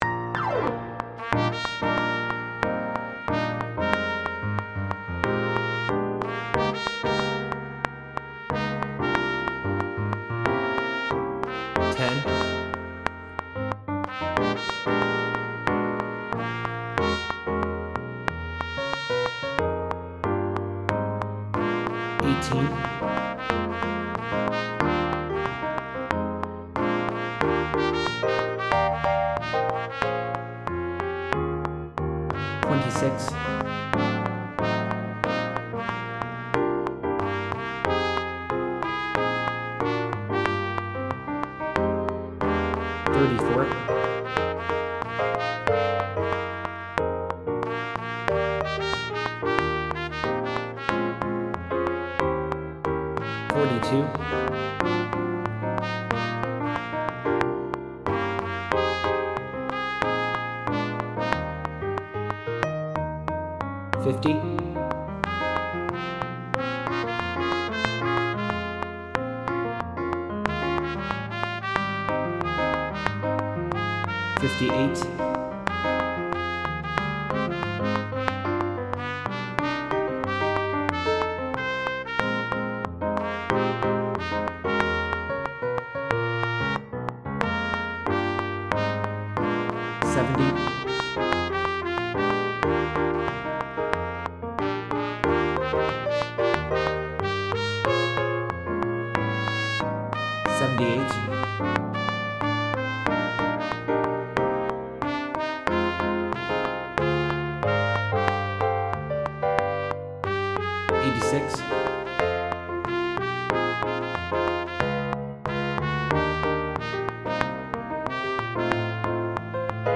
Sop 1